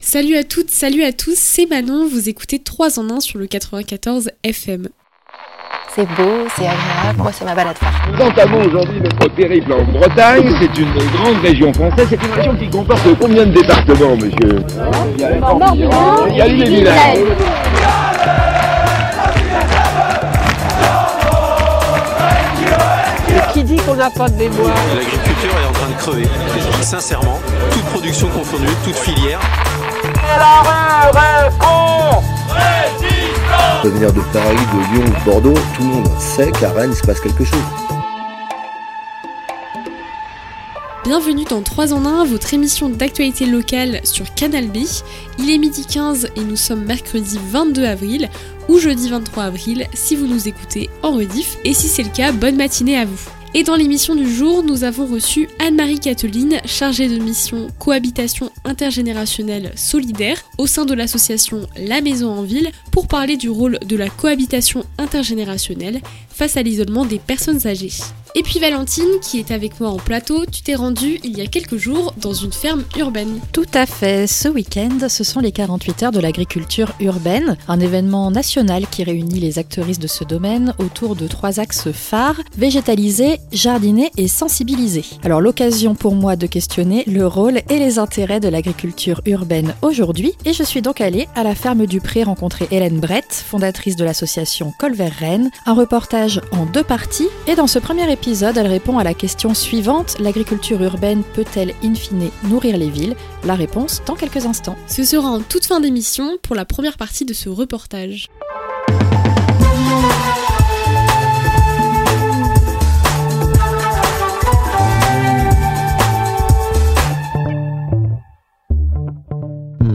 L’agriculture urbaine peut elle nourrir les villes ? 22/04/2026 Le billet d'humeur
L'interview
Le Reportage